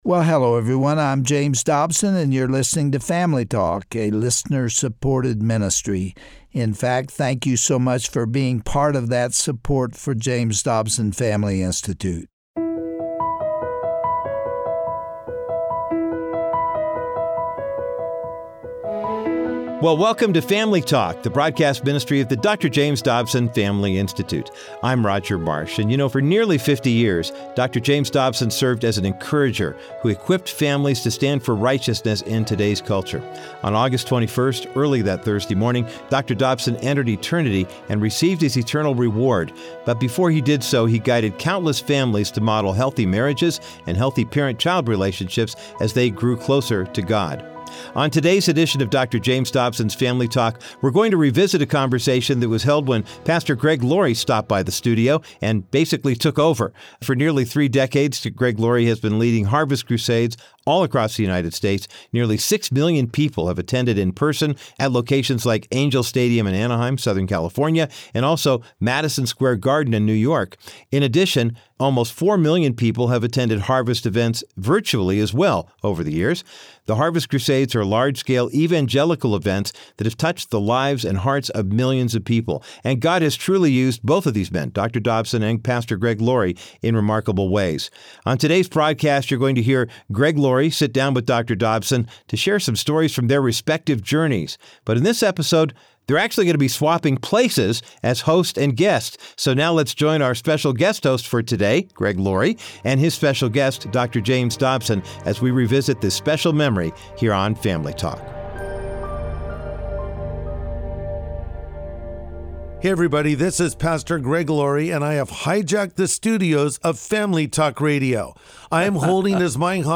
Turning the Tables: Greg Laurie Interviews Dr. James Dobson - Dr. James Dobson Family Institute